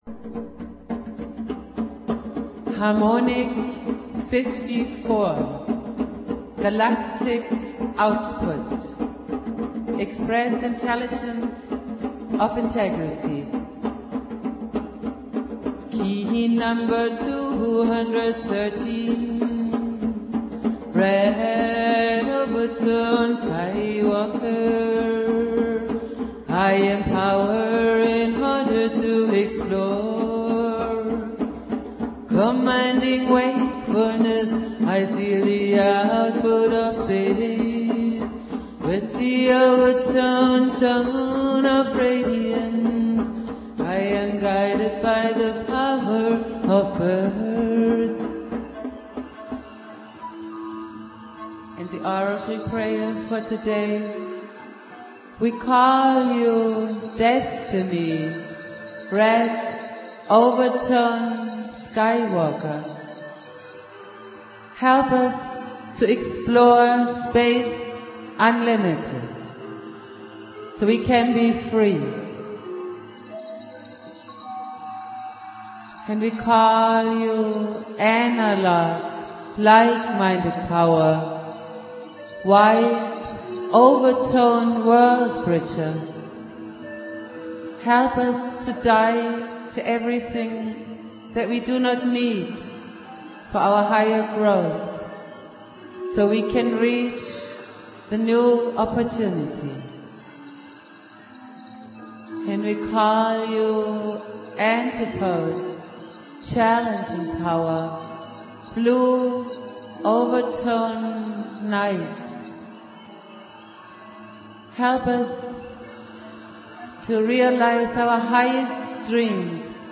Jose Arguelles - Valum Votan playing flute
Prayer
produced at High Flowing Recording Studio